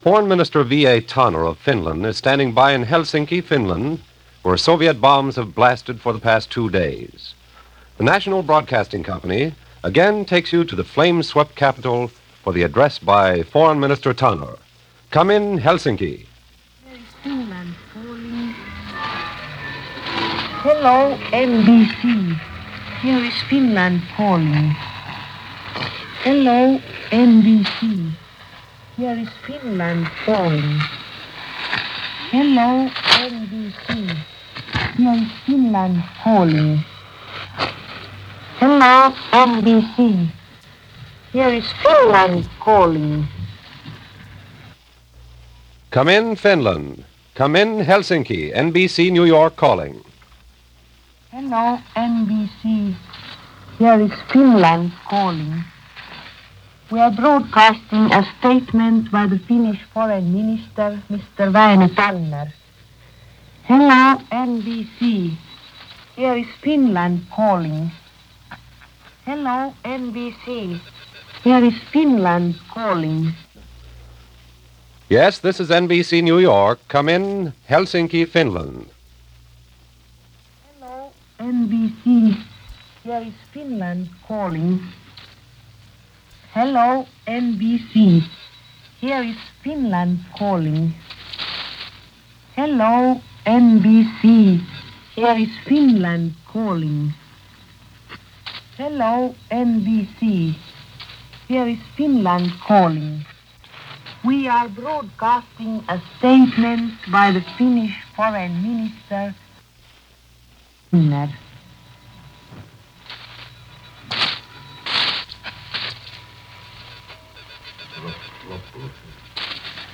Foreign Minister Vaino Tanner declared in a radio address to the United States that the Finnish nations independence and integrity could be destroyed by their eastern neighbor, Russia. He referred to the ruptured negotiations on the Russian territorial demands and declared that Russias invasion brought bitter disappointment, shared by the entire nation.
Vaino-Tanner-Address-Decmeber-2-1939.mp3